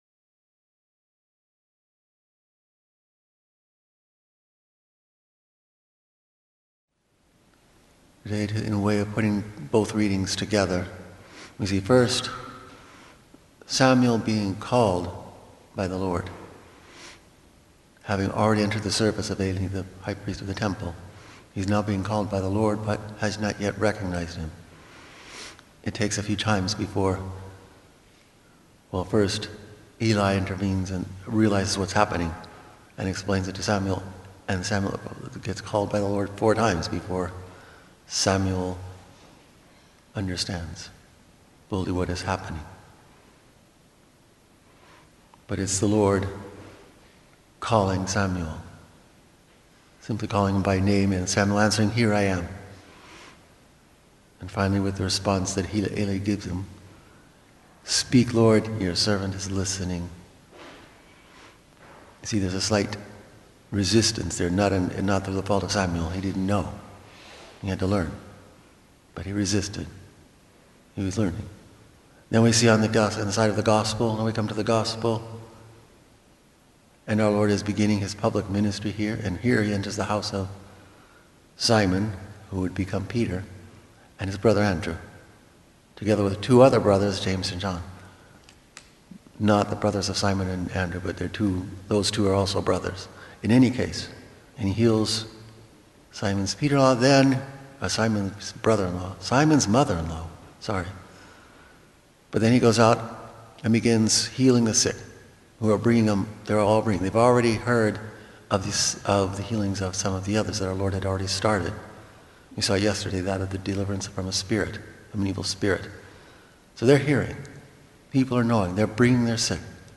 Homily
Mass: Wednesday 1st Week of Ordinary Time - Wkdy - Form: OF Readings: 1st: 1sa 3:1-10, 19-20 Resp: psa 40:2-5, 7-8, 8-9, 10 Gsp: mar 1:29-39 Audio (MP3) +++